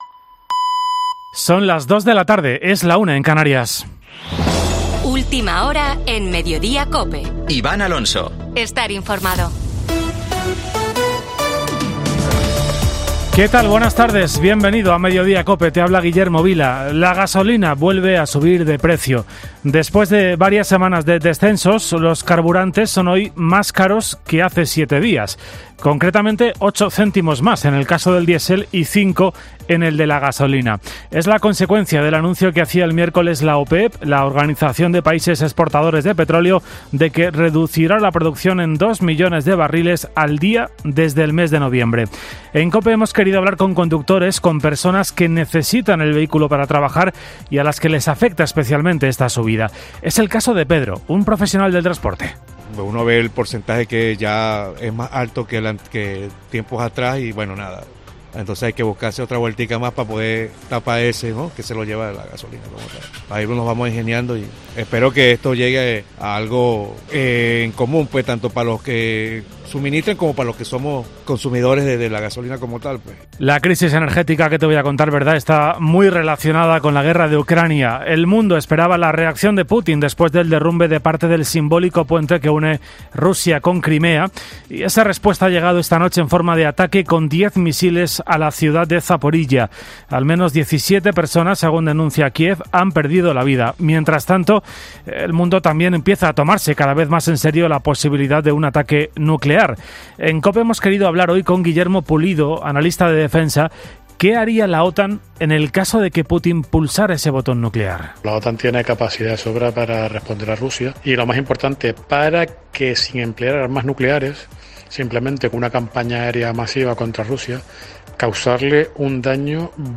Boletín de noticias de COPE del 9 de octubre de 2022 a las 14.00 horas